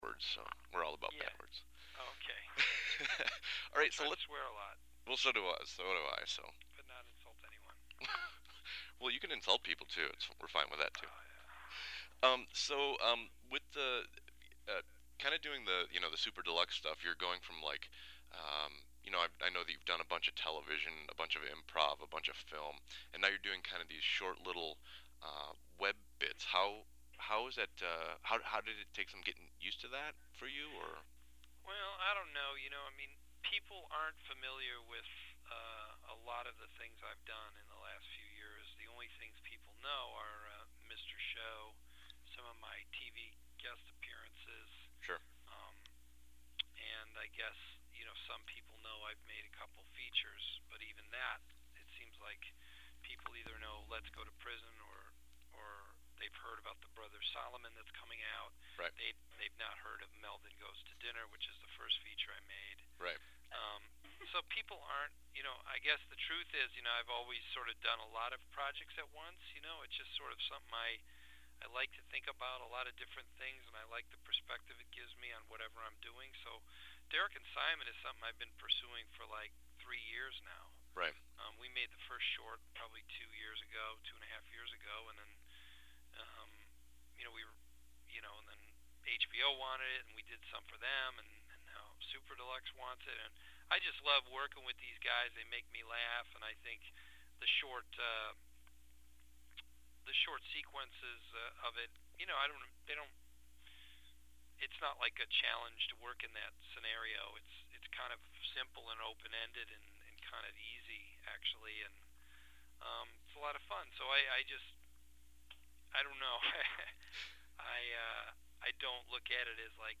LAUNCH BOB ODENKIRK INTERVIEW (MP3 FILE)(NOTE: File is large and may take awhile to fully load.)